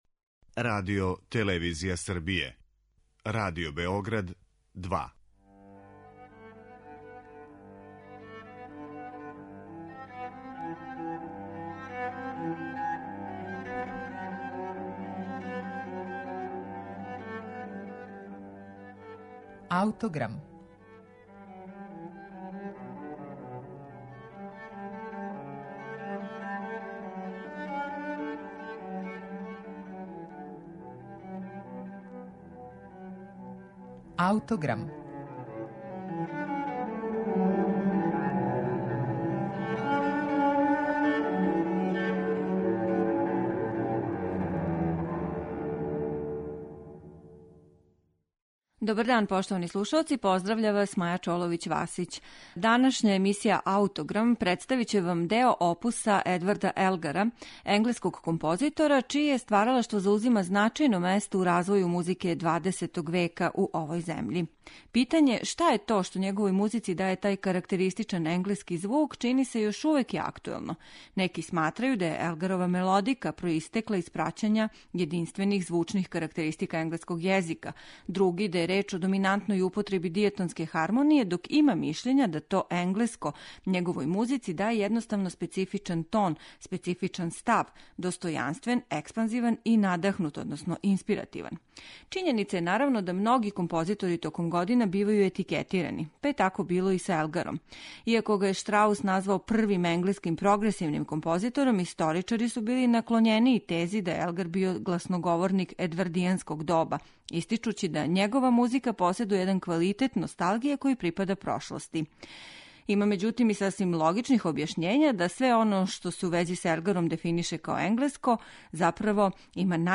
Критичари наглашавају рафинираност и продороност дела које су назвали великом камерном музиком и које повремено добија готово оркестарске димензије у звуку.